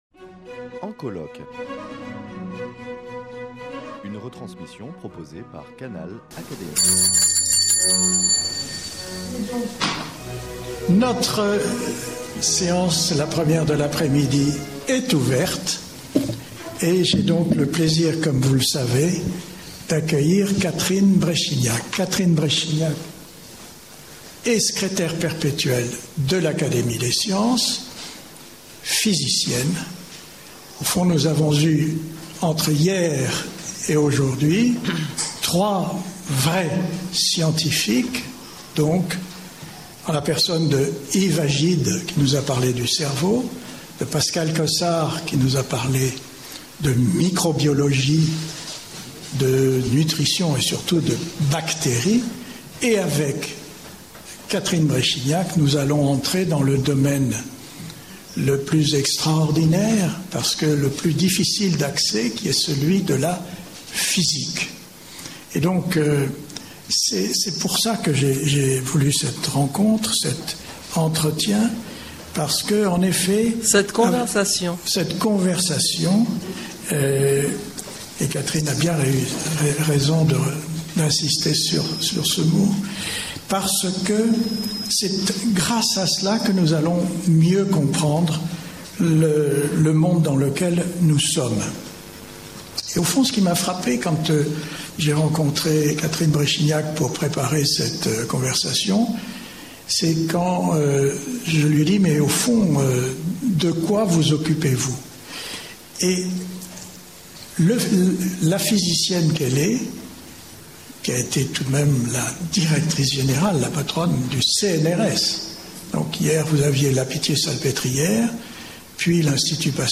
Animées par François d’Orcival, membre de l’Académie des sciences morales et politiques, organisées à la Fondation Dosne-Thiers à l’occasion des journées du patrimoine 2018 (2e partie).